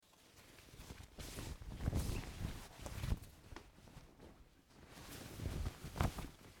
Звуки футболки
Шорох футболки скользящей по телу